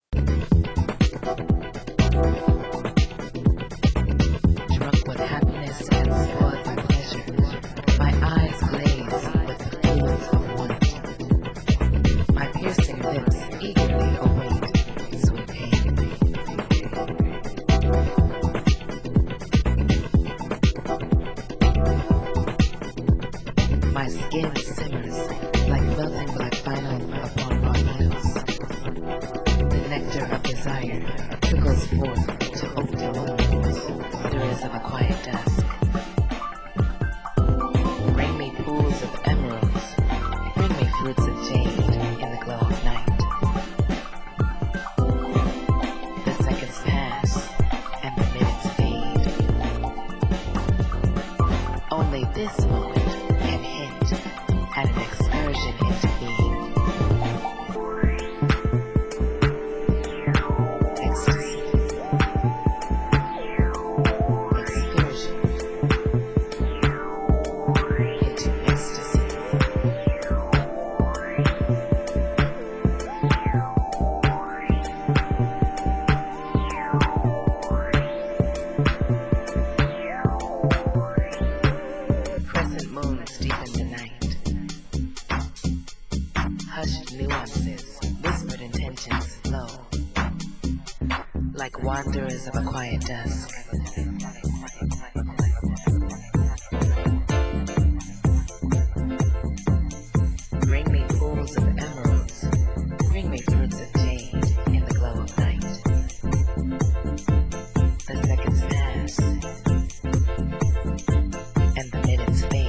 Genre Euro House